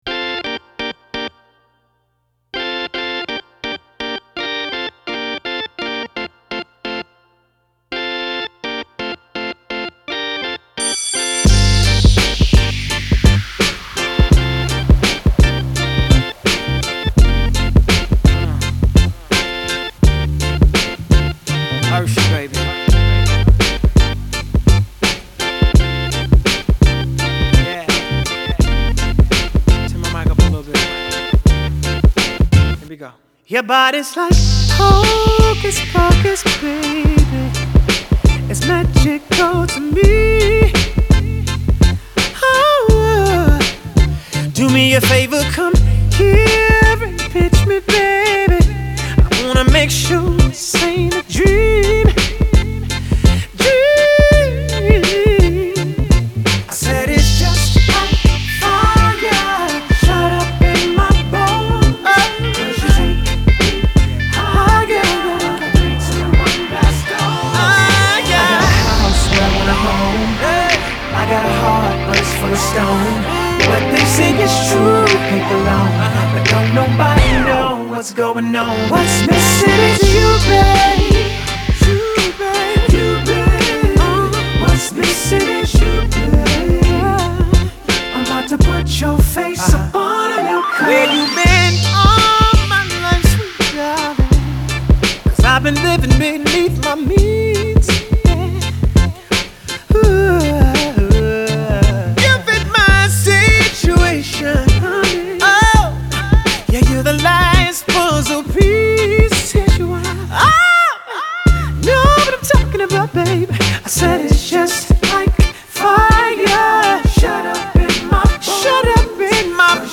It’s a good track with good R&B legs.